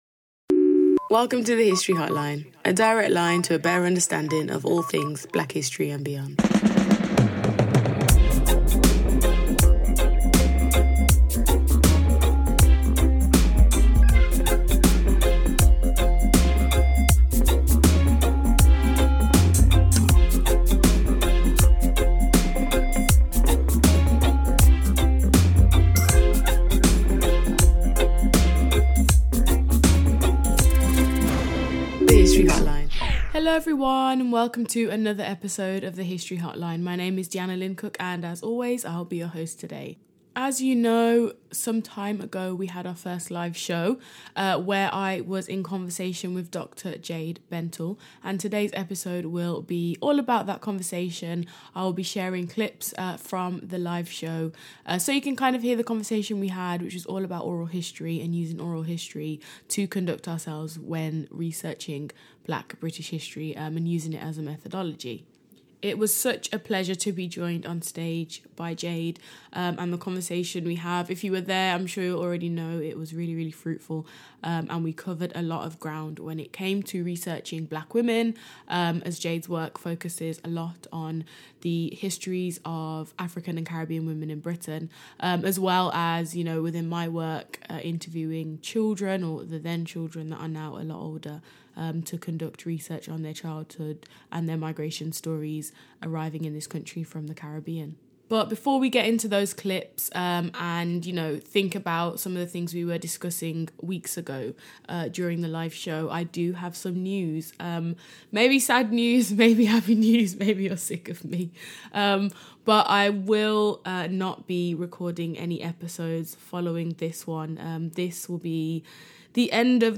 this last episode is taken from the live show